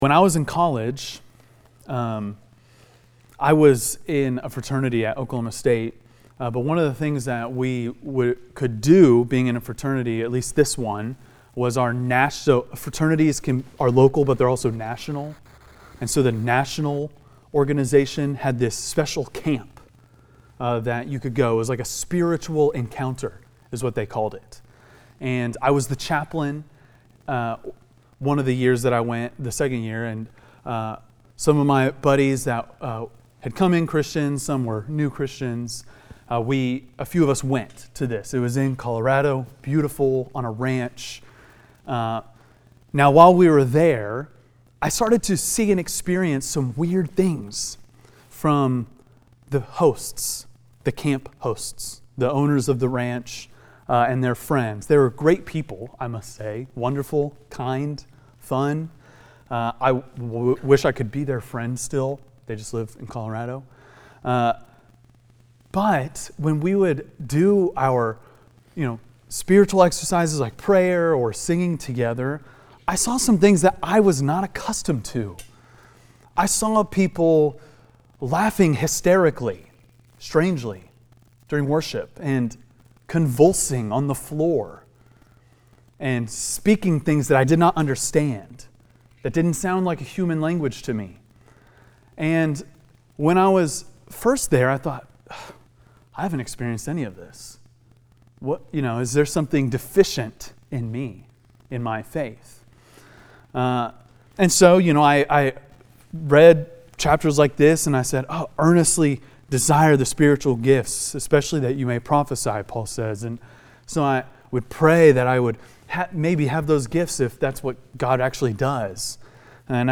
preaches through 1 Corinthians 14.